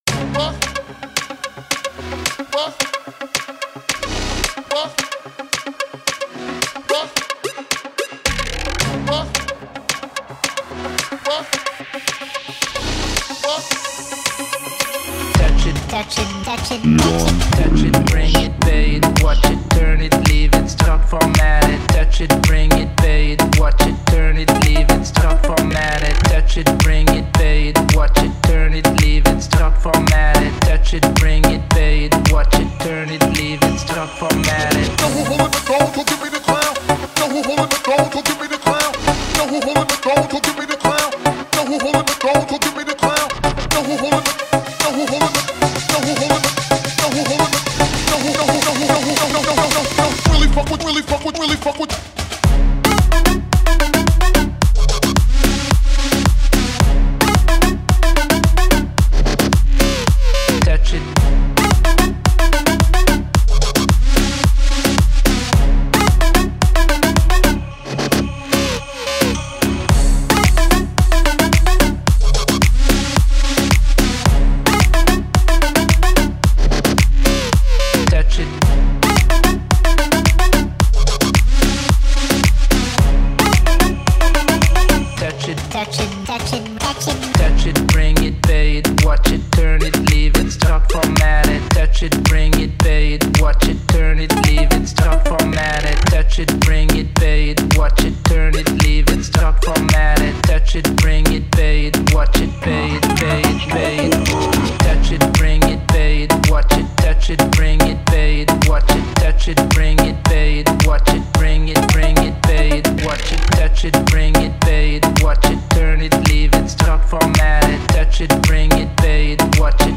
Download Remix Club